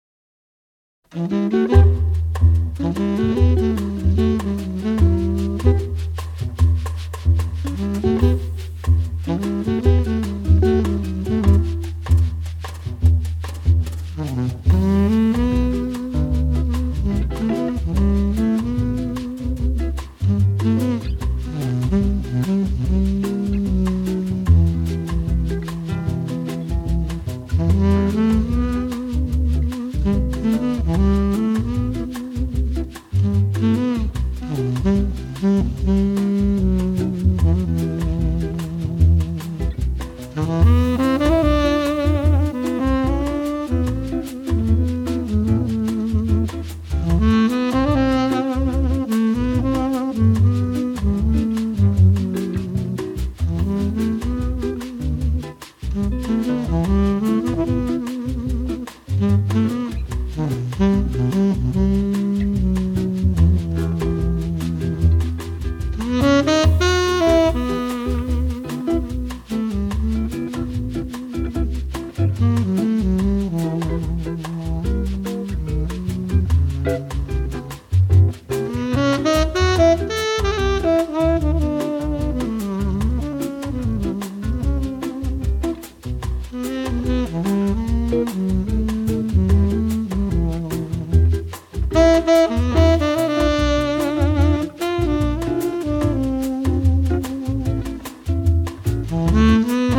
★ 美麗質樸、輕鬆優雅的薩克斯風語調，集柔和、浪漫、慵懶、甜美與性感等於一身。
tenor sax
guitar
bass
drum
chekere.